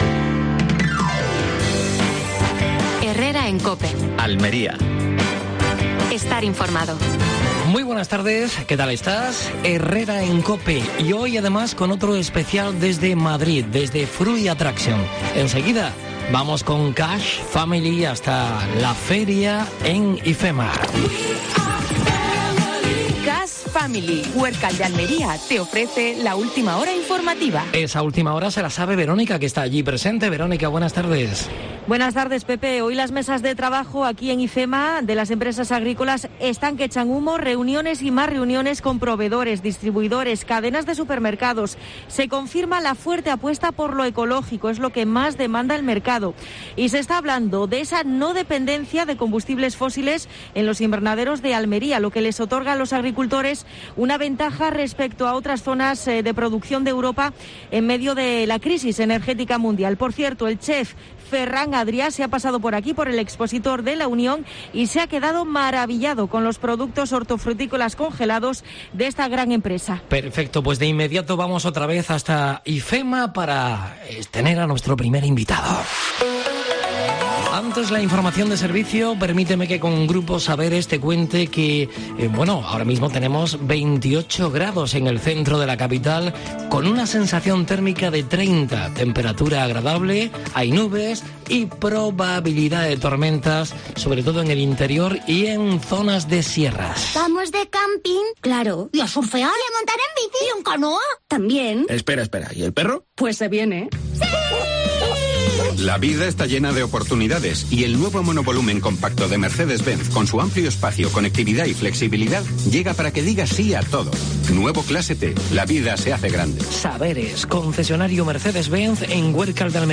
AUDIO: Desde Fruit Attraction (Madrid). Entrevista a Juanjo Segura (concejal de Agricultura de Almería).